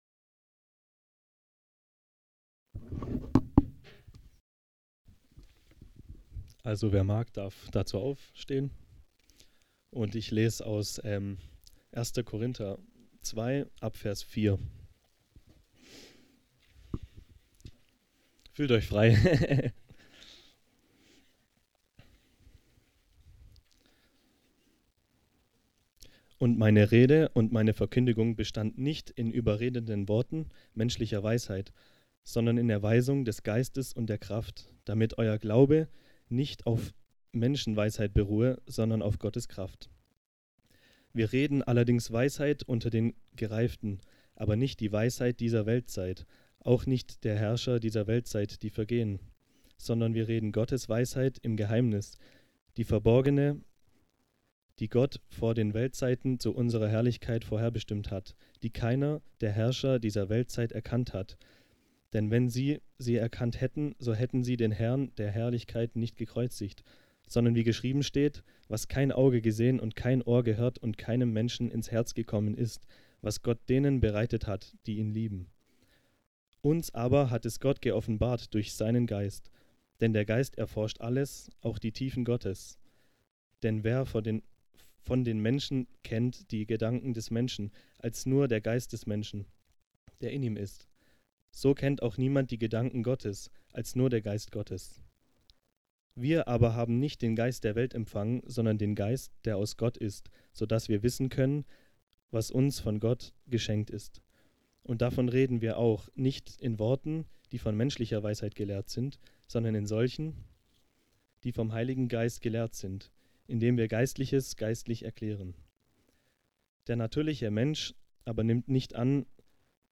Exegetische Predigt - Christliche Versammlung Sindelfingen - Page 2